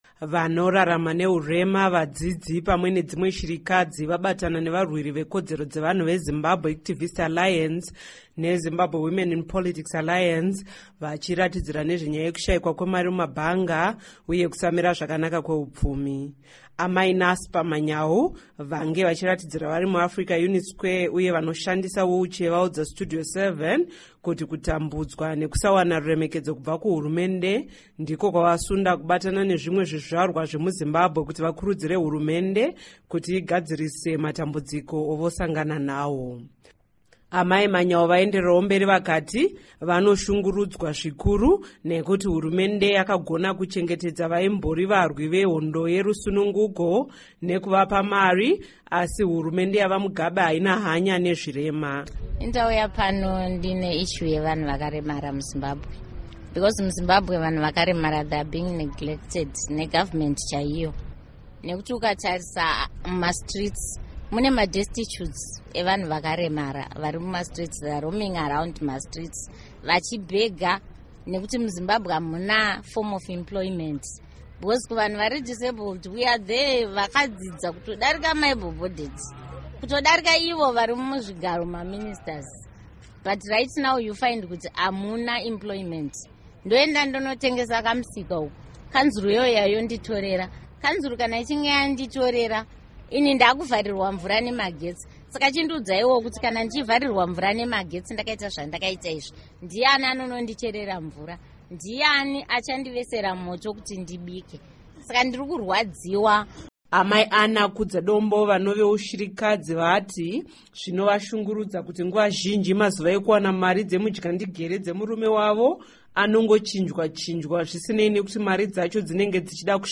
Report on Civic Society Activists